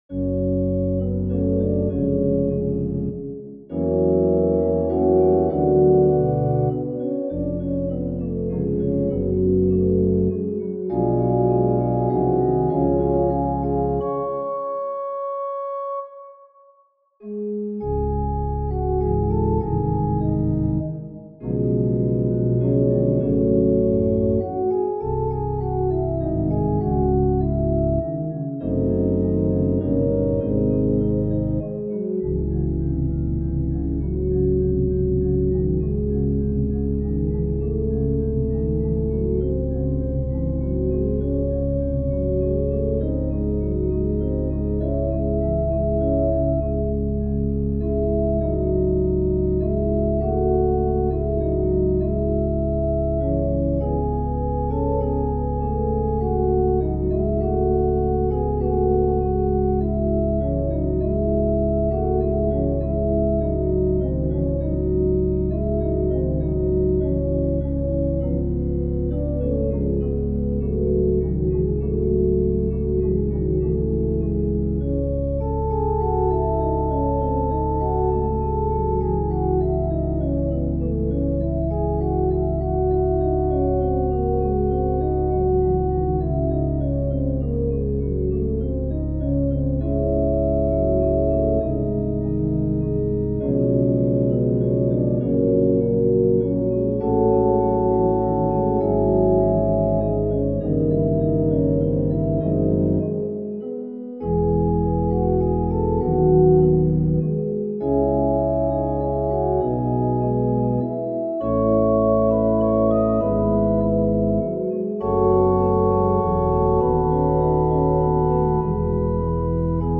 Fantasia and Fugue in f# minor - Piano Music, Solo Keyboard - Young Composers Music Forum
Fantasia and Fugue in f# minor